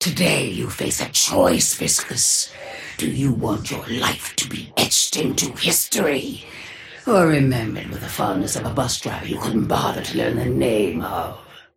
Sapphire Flame voice line - Today you face a choice, Viscous.
Patron_female_ally_viscous_start_11_02.mp3